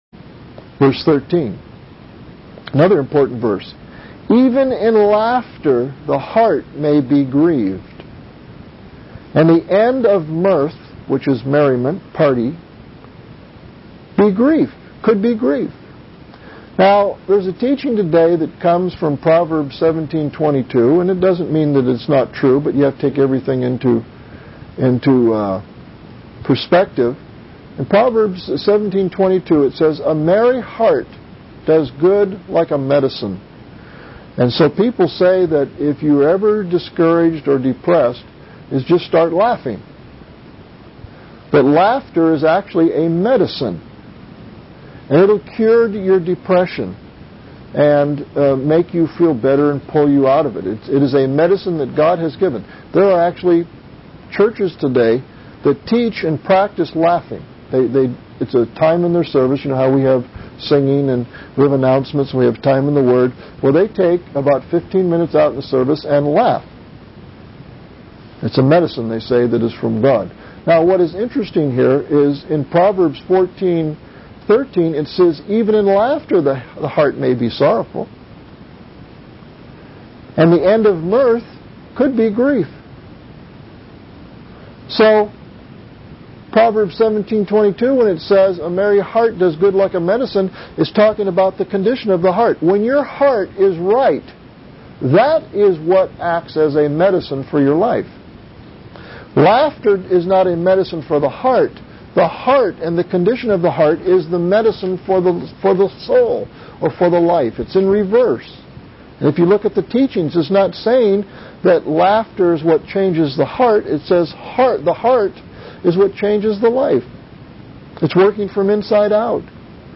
1. Devotionals
excerpt from Proverbs 14:1-14 (old series)